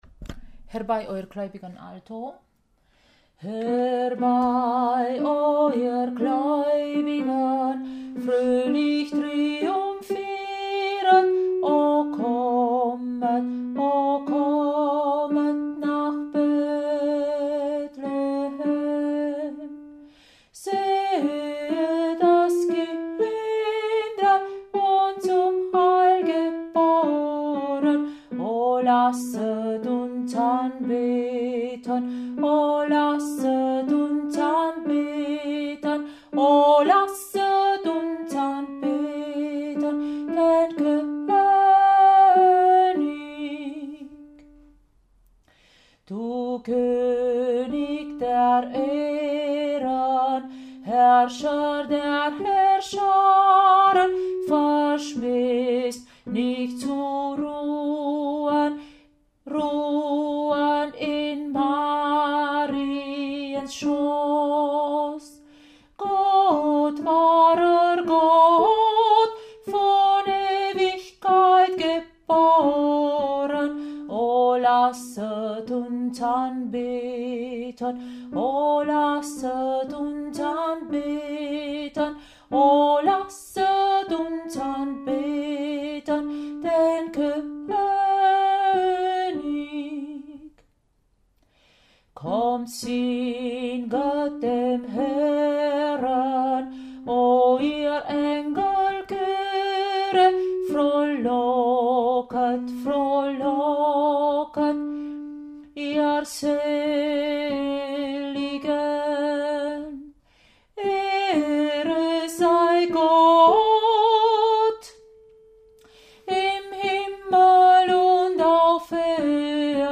Herbei oh ihr Gläubigen Alto
Herbei-oh-ihr-gläubigen-Alto.mp3